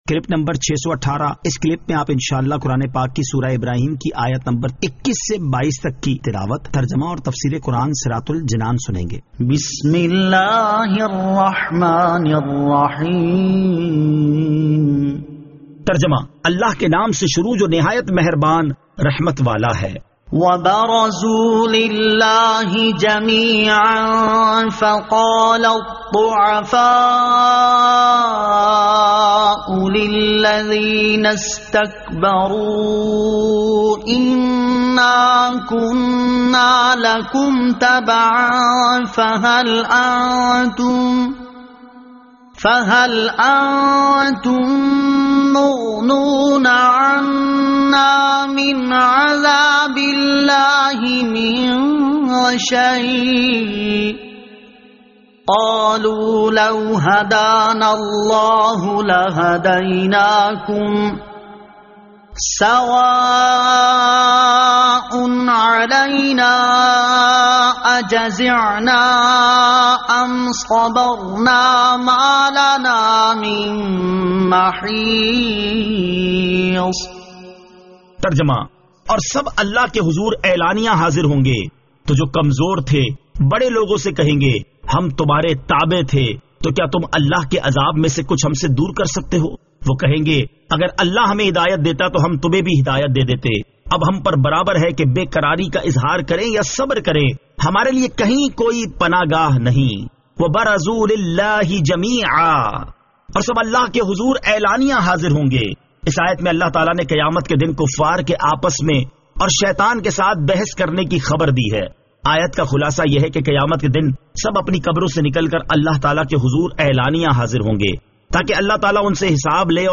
Surah Ibrahim Ayat 21 To 22 Tilawat , Tarjama , Tafseer